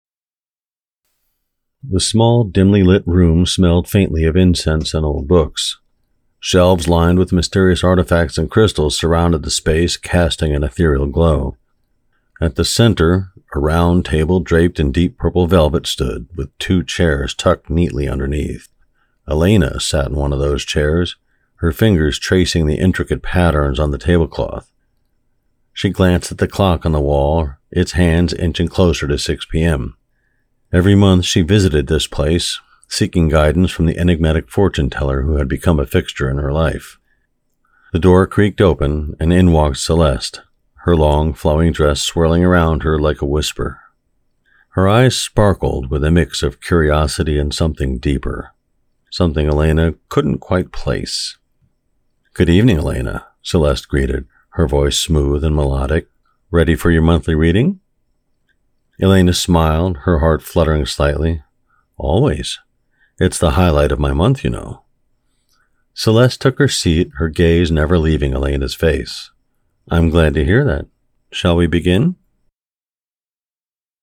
Audio Book sample read
Senior